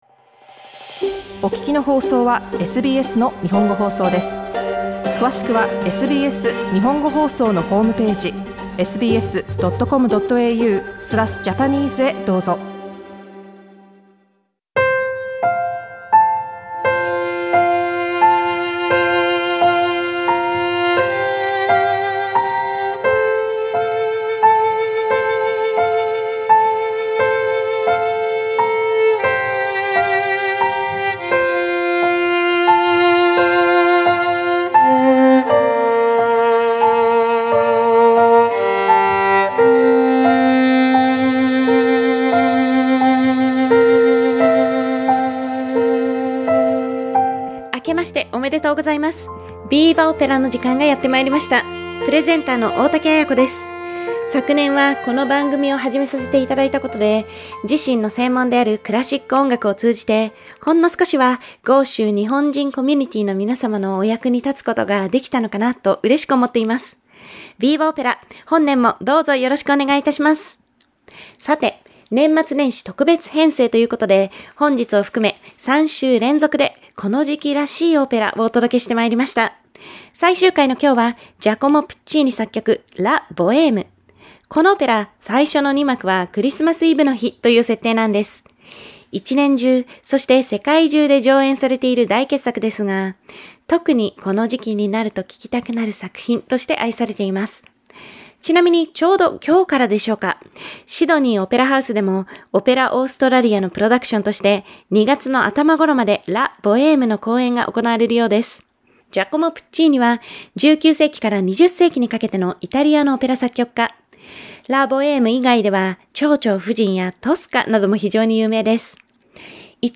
Opera 8） VIVA! Opera is a monthly content broadcast on the last Tuesday of each month.